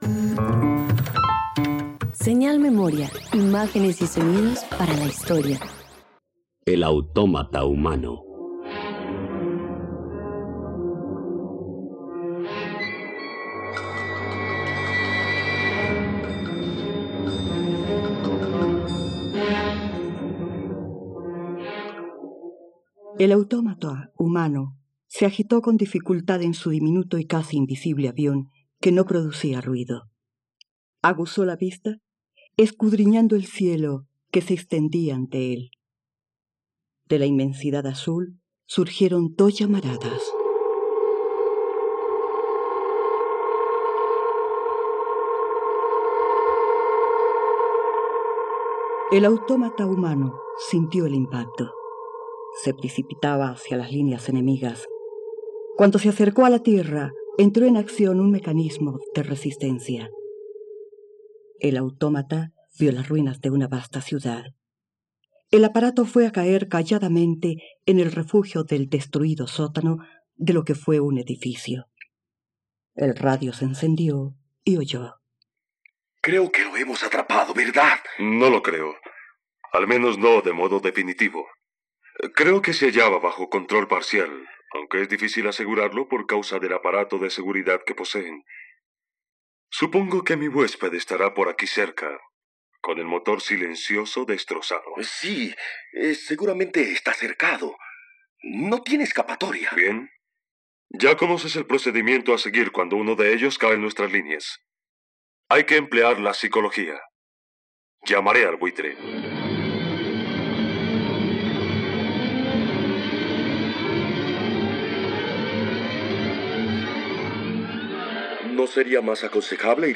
El autómata - Radioteatro dominical | RTVCPlay